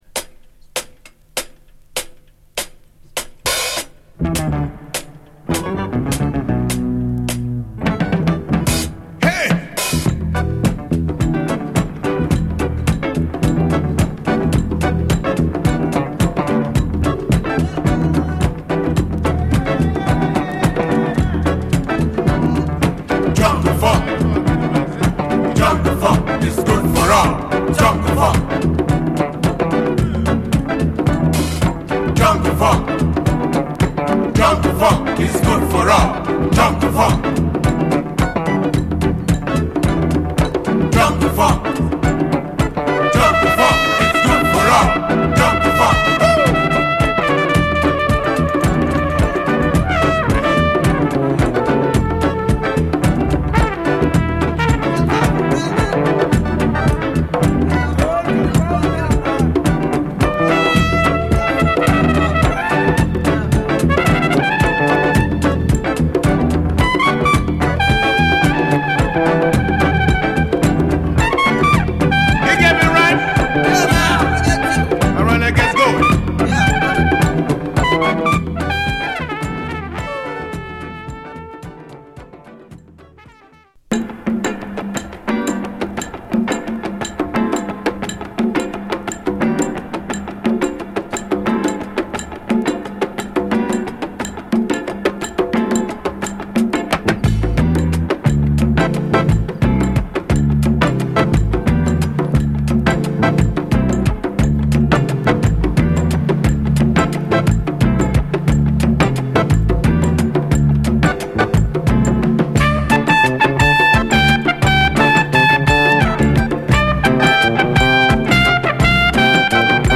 ガーナ産レアグルーヴ
ミドル・テンポのファンク・チューン
ガーナらしい土着的なパーカッションに、ファンキーなギター・カッティング、サイケデリックなファズ・ギターが絡みつく
レイドバックした雰囲気とハイライフのような哀愁を感じされるメロディーが素晴らしい